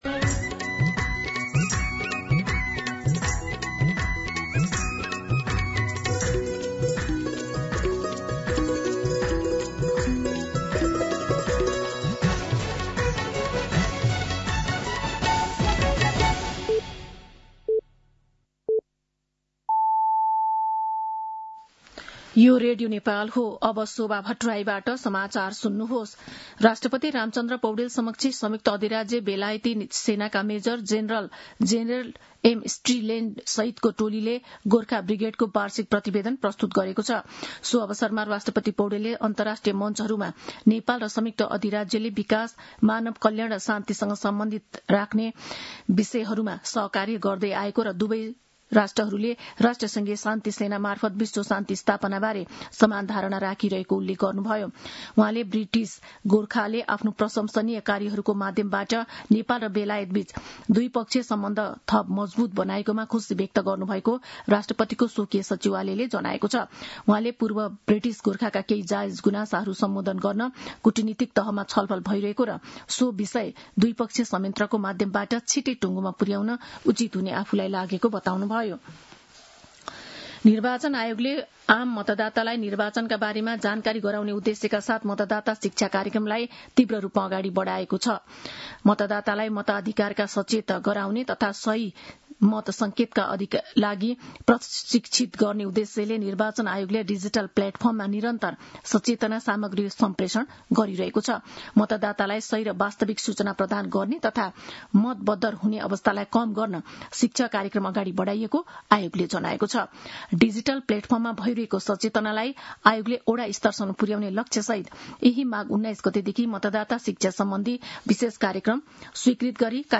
दिउँसो १ बजेको नेपाली समाचार : २६ माघ , २०८२